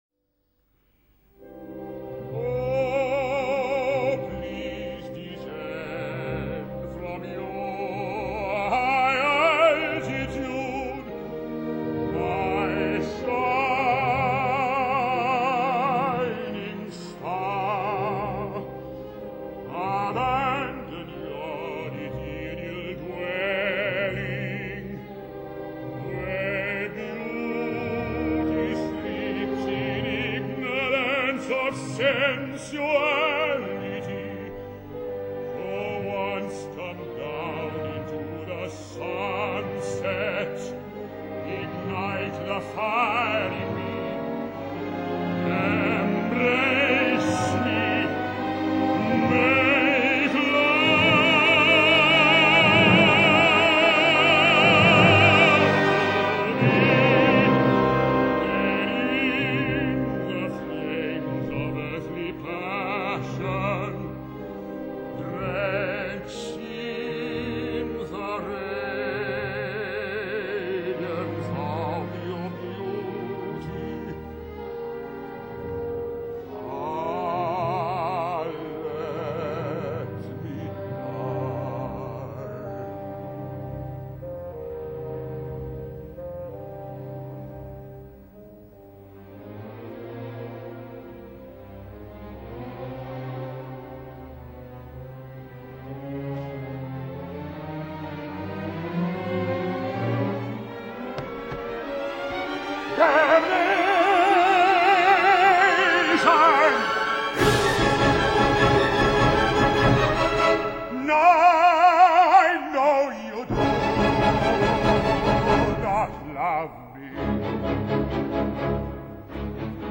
Francesca da Rimini - Opera North - 2004